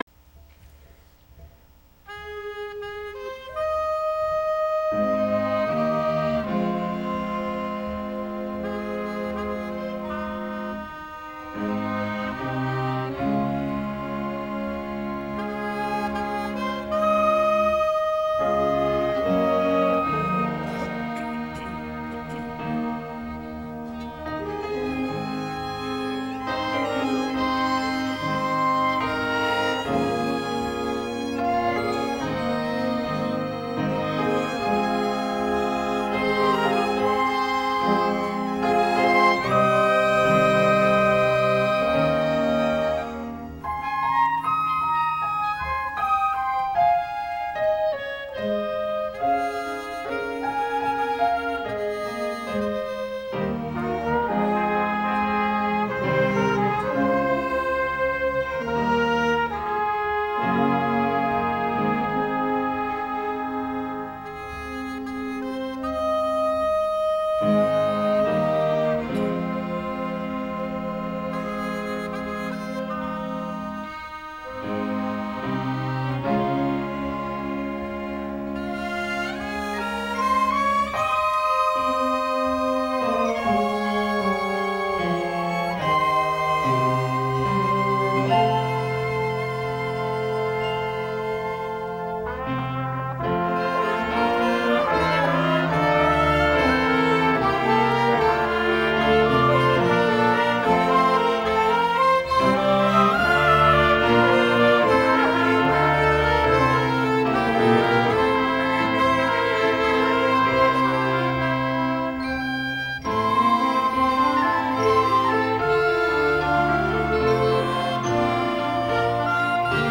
“Amazing Grace” and “I Love You” – Orchestra and Choir combined”.
amazing-grace-orchestra-and-i-love-you-choir-combined.mp3